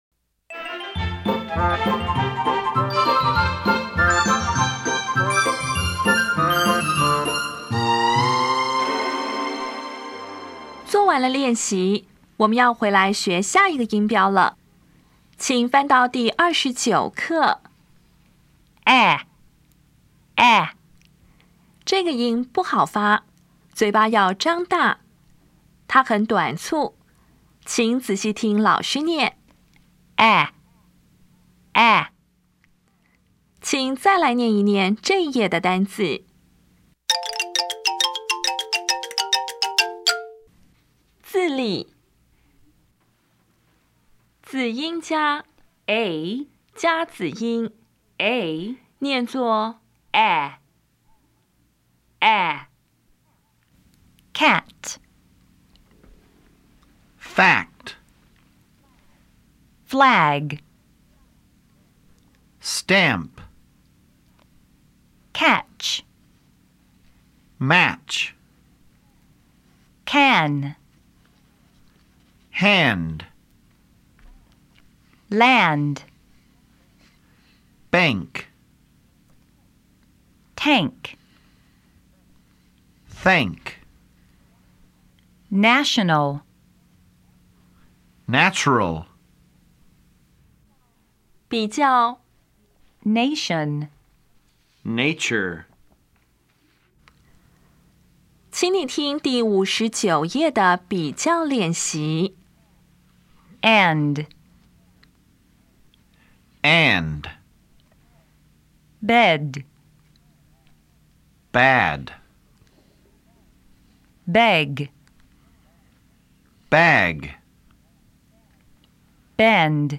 当前位置：Home 英语教材 KK 音标发音 母音部分-1: 短母音 [æ]
音标讲解第二十九课
[kæt]
比较[ɛ] 与 [æ]